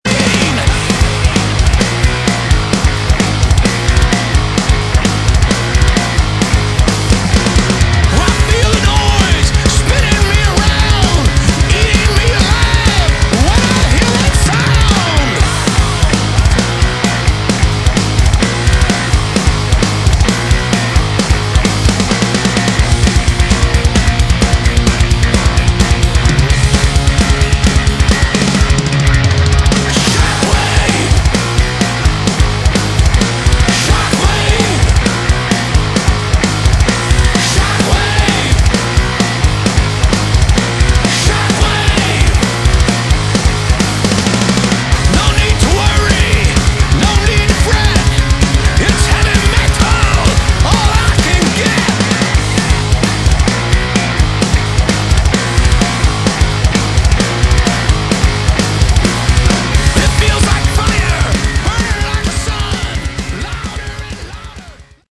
Category: Melodic Metal
vocals, guitar
drums, percussion, vocals
bass, vocals